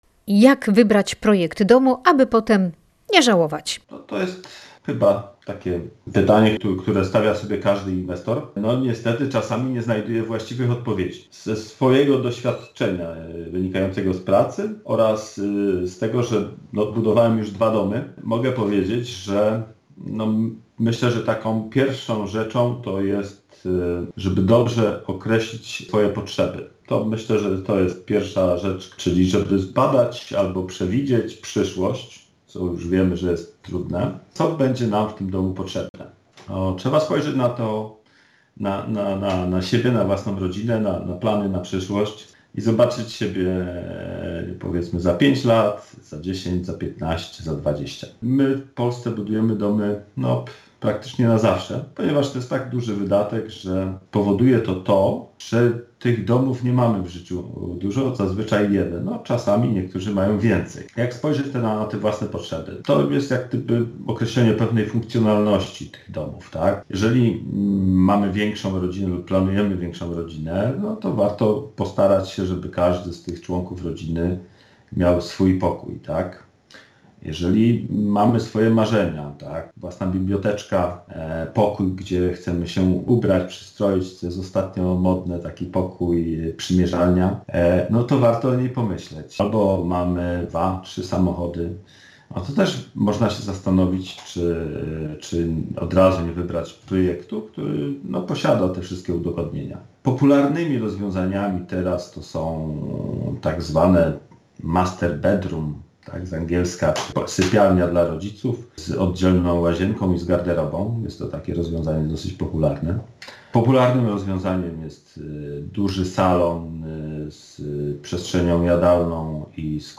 Wyjaśnia specjalista